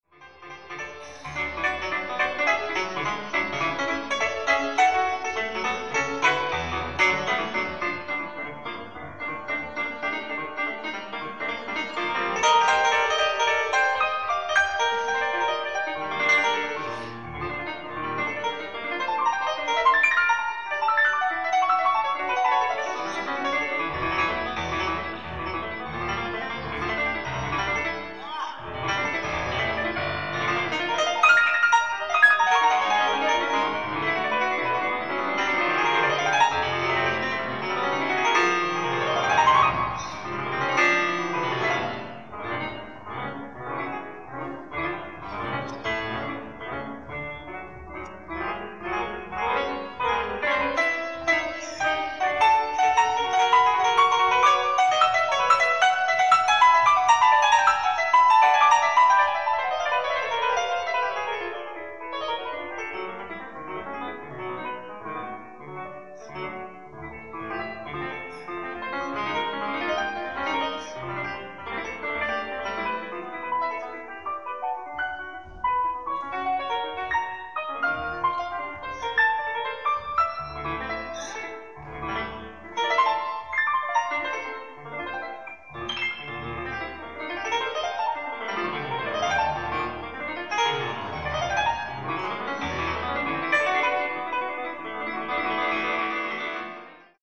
ライブ・アット・サン ソヴァール スポーツ パレス、リール、フランス
勿論、マスターの劣化によるノイズを可能な限り軽減しナチュラルな音像でご堪能頂けるタイトルとして登場しました。
※試聴用に実際より音質を落としています。